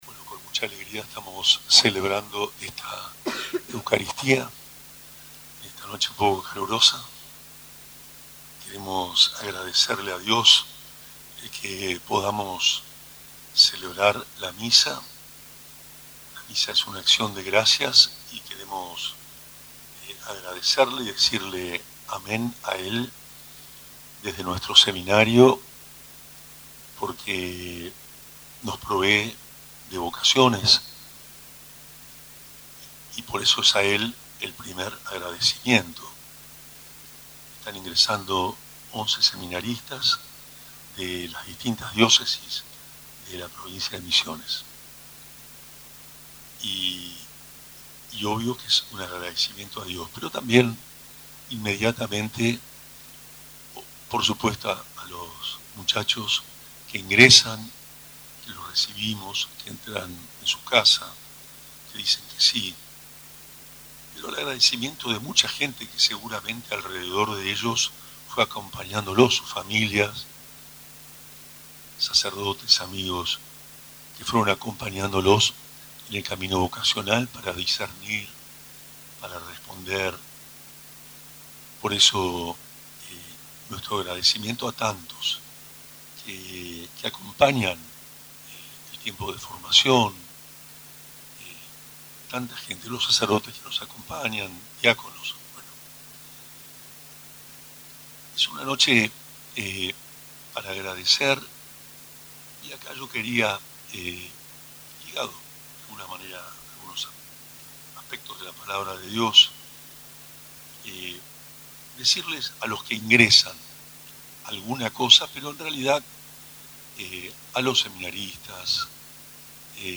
El sábado 1 de marzo de 2025, a las 20:00, se llevó a cabo la Misa de Inicio en el Patio del Seminario Diocesano "Santo Cura de Ars", con una notable convocatoria de fieles.
MISA-SEMINARIO-HOMILIA.mp3